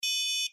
11. Платежный терминал сообщает про успешную транзакцию